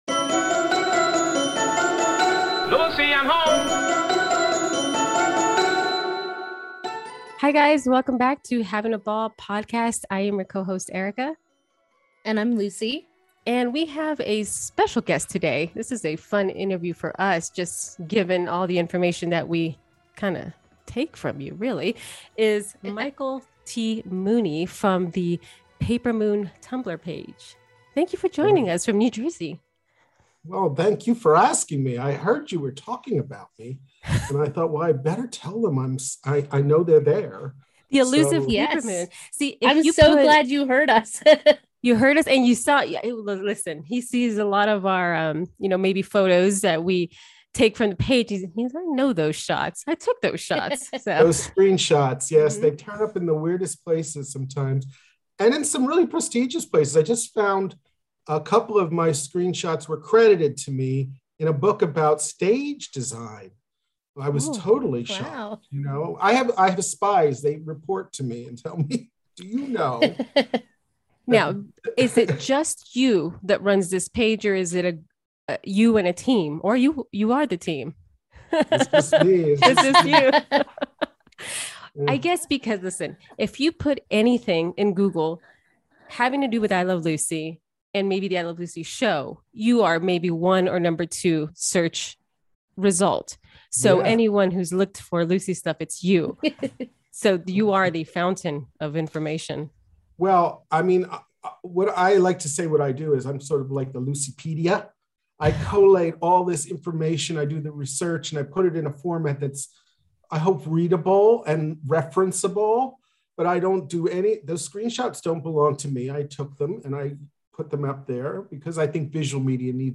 We chat about what he did (and currently still does) in theatre, his love of Lucille Ball, and how his ever-growing page began. As anyone who appreciates background trivia (such as sponsor placements, hidden gems and everyday items used on the show), bloopers, and history weaving-in of information, this was quite a fun interview.